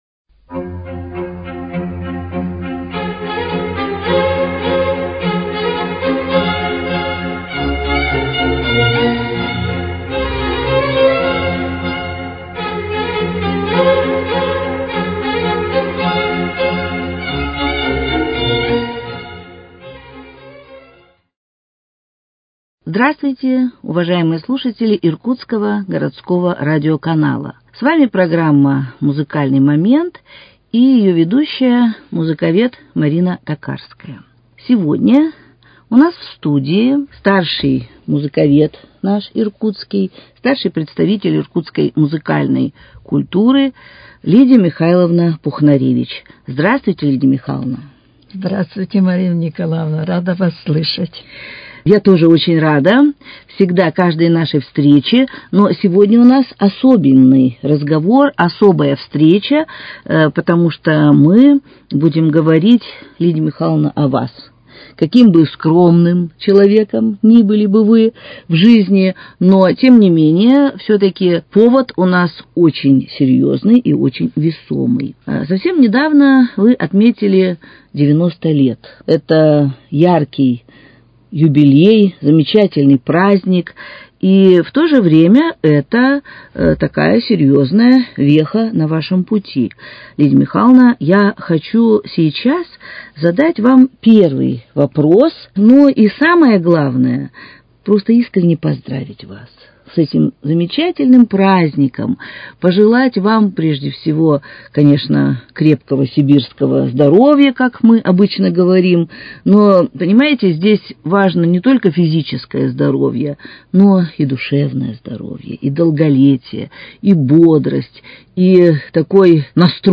Музыкальный момент: Беседа